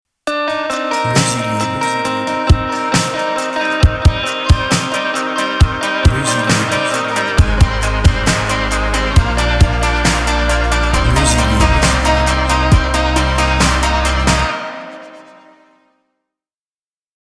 BPM Lent